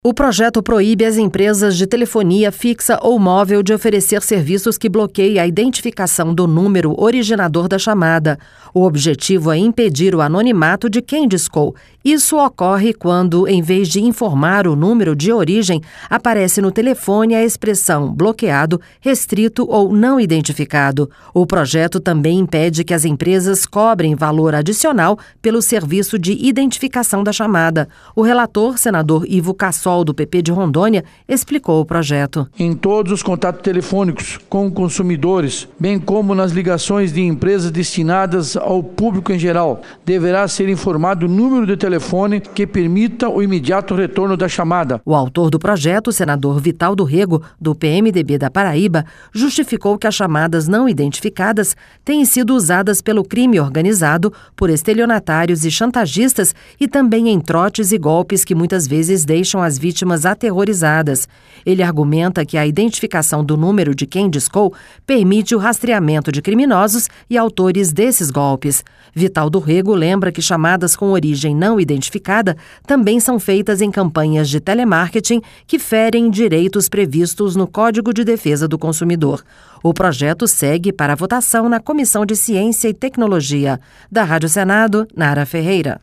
Senador Ivo Cassol